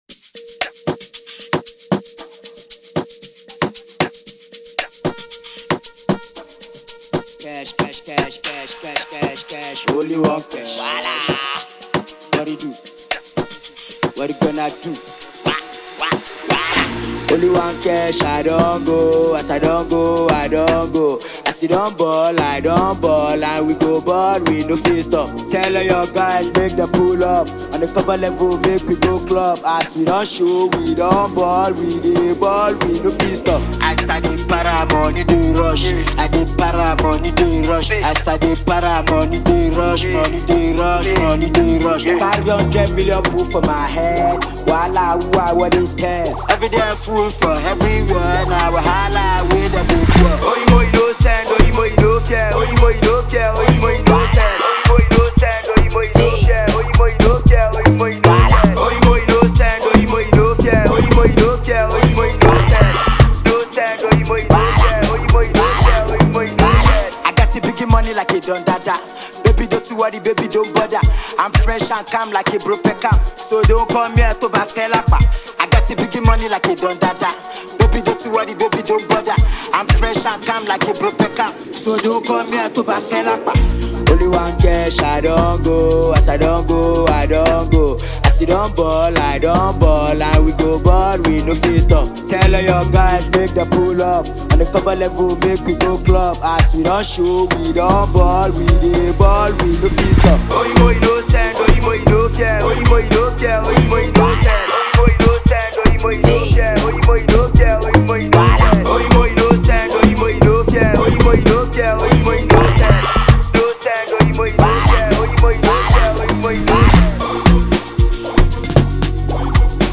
This new Amapiano influenced sound from the singer